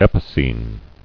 [ep·i·cene]